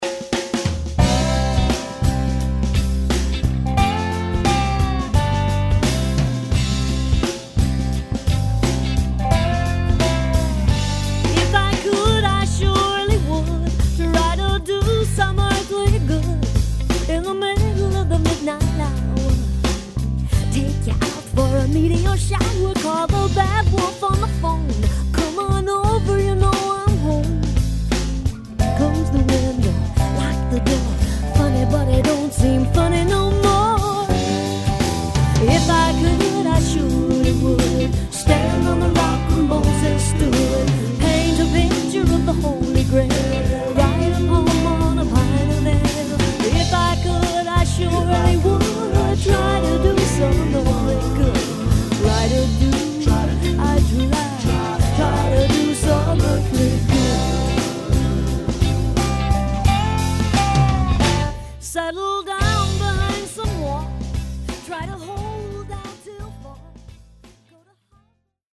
Percolating, moody, and soulful